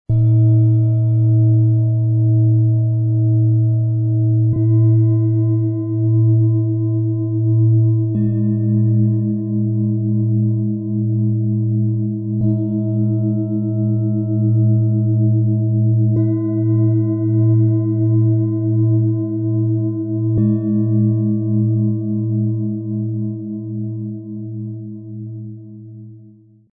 Eine tiefe, fast mütterliche Geborgenheit liegt im Klang des Mondes.
Jeder Ton dieser Schalen wirkt wie eine sanfte, beruhigende Berührung.
Im Sound-Player - Jetzt reinhören können Sie den Original-Ton genau dieser Schalen, des Sets anhören.
Tiefster Ton: Mond Siderisch
Bengalen Schale, Matt, 21,3 cm Durchmesser, 9,4 cm Höhe
Mittlerer Ton: Mond Metonisch
Höchster Ton: Mond Synodisch
Bihar Schale, Matt, 17,5 cm Durchmesser, 9,5 cm Höhe